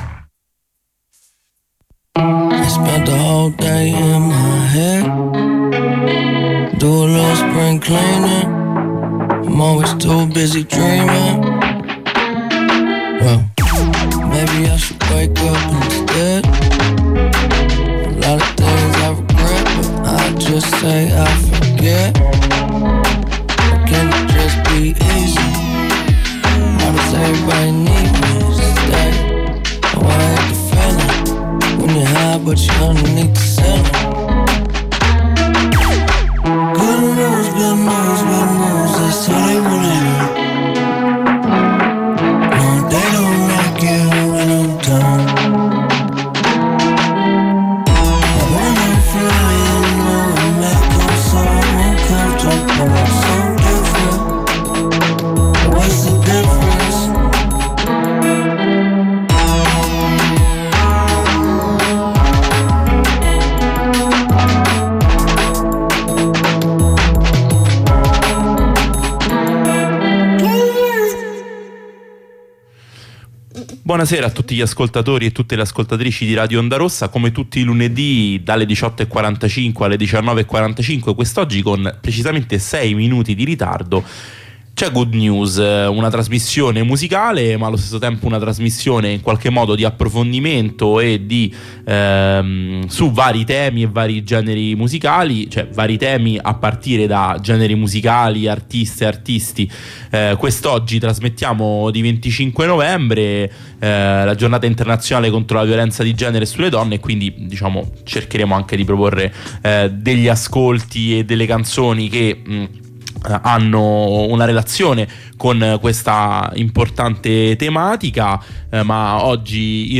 Questo lunedì terza puntata playlist, redazione al completo, chiacchiere e brani pescati dagli ascolti delle ultime settimane. La puntata di oggi cade di 25 novembre, giornata internazionale contro la violenza di genere e sulle donne, e per questa importante occasione proponiamo la seguente selezione: Playlist: